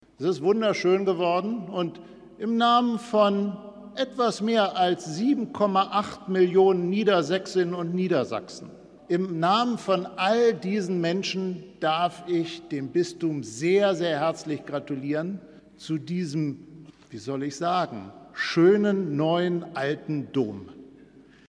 Ministerpräsident Stephan Weil während des Gottesdienstes zur Eröffnugn des Hildesheimer Doms.